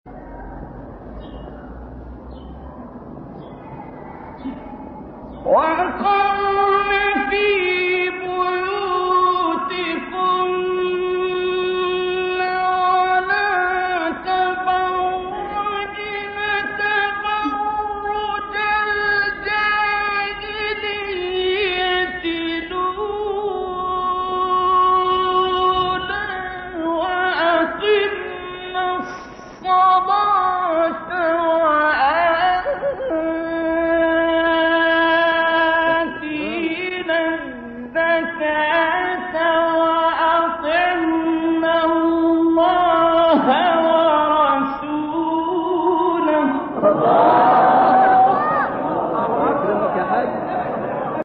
گروه شبکه اجتماعی: مقاطع صوتی از تلاوت قاریان بنام و برجسته جهان اسلام که در شبکه‌های اجتماعی منتشر شده است، می‌شنوید.